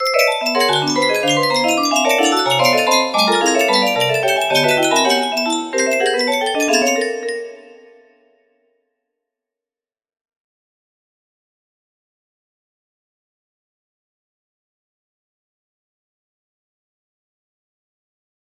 bla bla music box melody